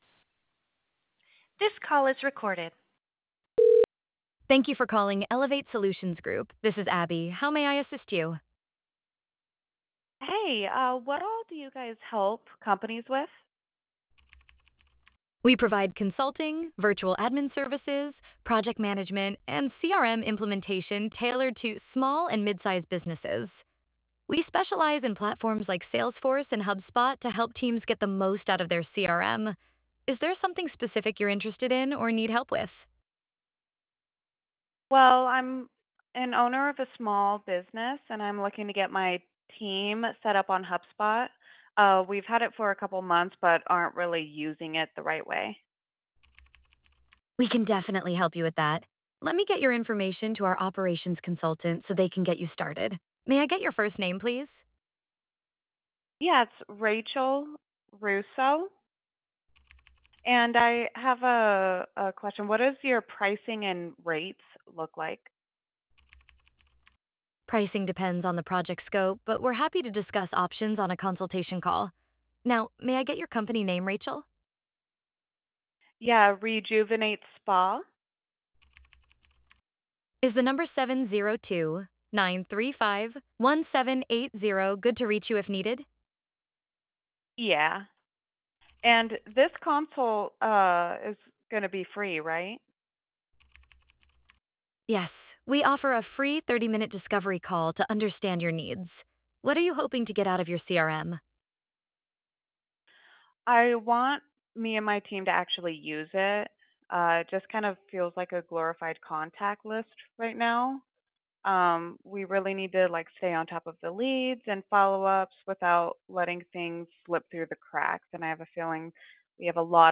Listen to a demo to hear Abby’s receptionists taking real calls like yours!
AI Receptionist
SAMPLE CALL
AI-Business-Services.wav